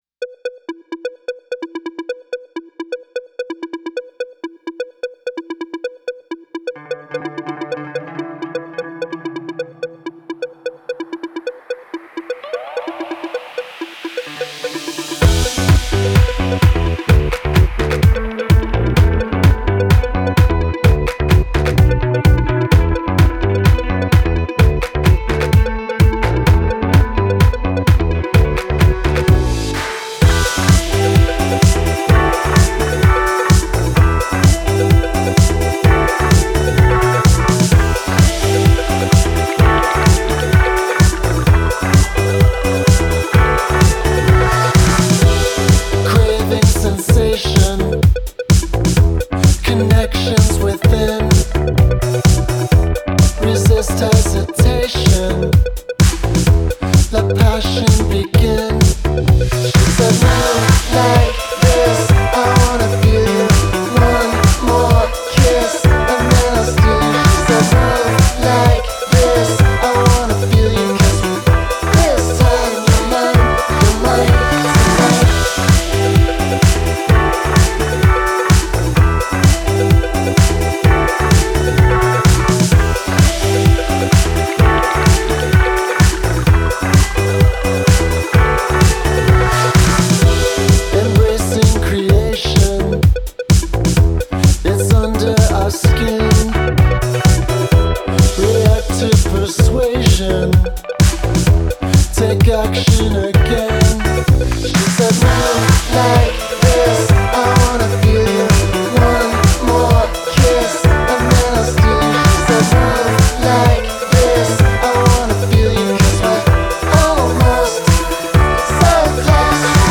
Genre: Indie, Dance Punk